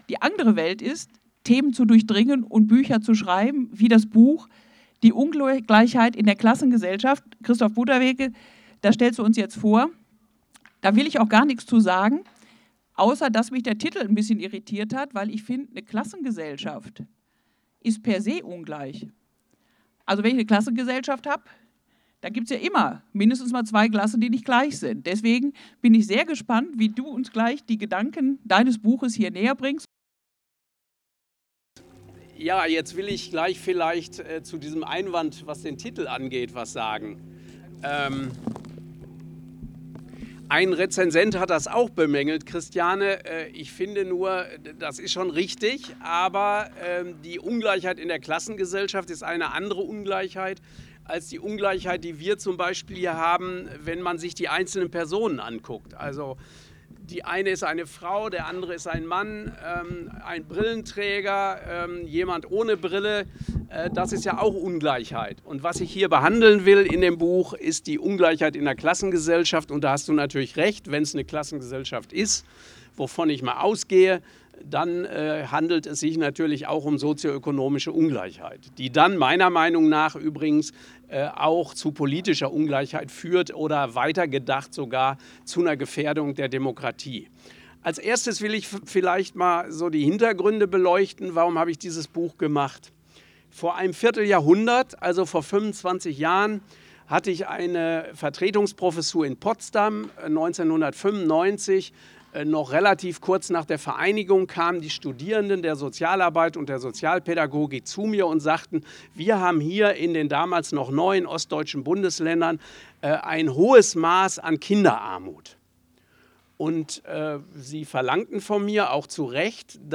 Ungleichheit in der Klassengesellschaft, Veranstaltung